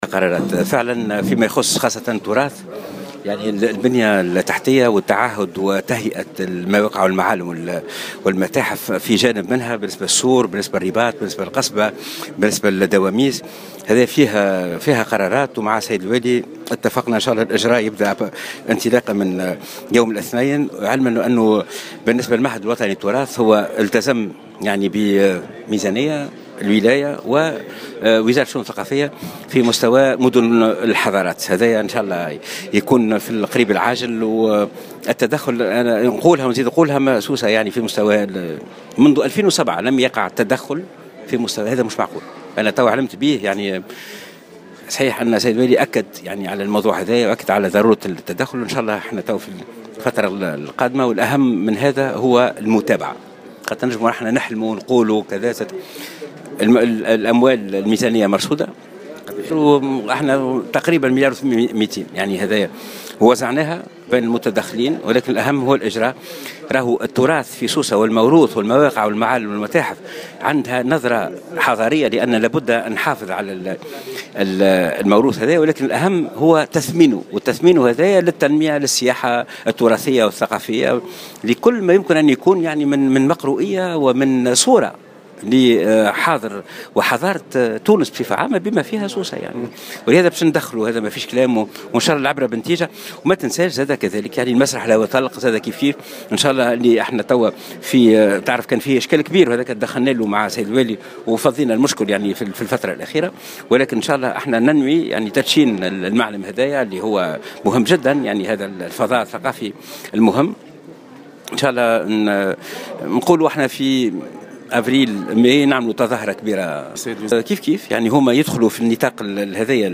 أكد وزير الشؤون الثقافية محمد زين العابدين على هامش زيارة إلى سوسة اليوم، في تصريح لموفد "الجوهرة أف أم" انه تم رصد اعتمادات لفائدة مواقع اثرية في سوسة وأبرزها السور والرباط والدواميس بالاضافة إلى مسرح الهواء الطلق.